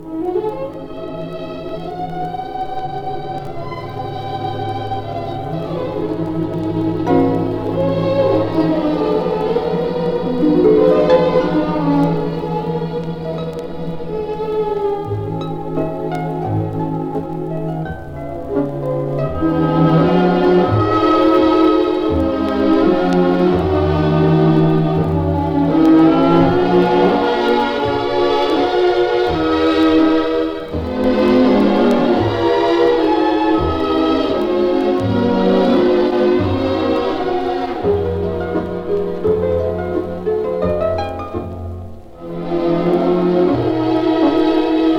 Jazz, Pop, Easy Listening　UK　12inchレコード　33rpm　Mono/Stereo